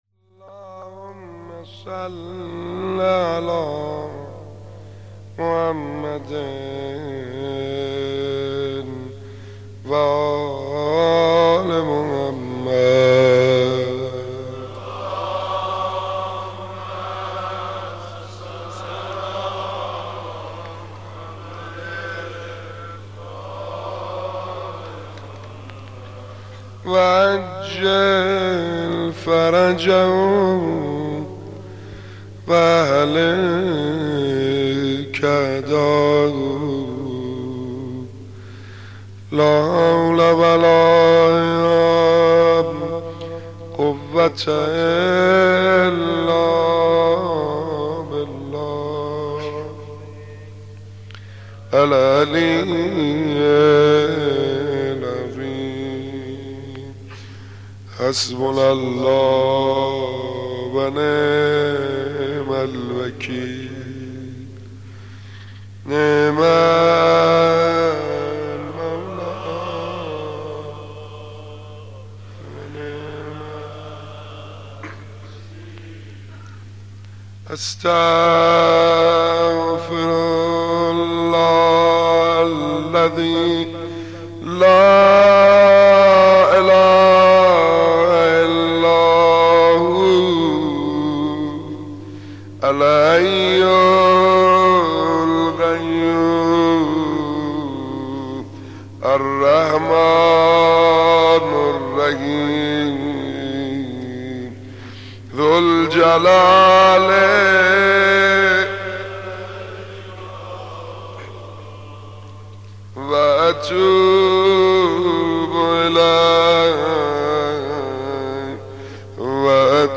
مناسبت : شب چهارم محرم
مداح : سعید حدادیان